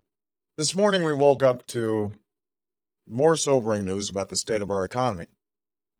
obama_clone_ref_after_resemble.wav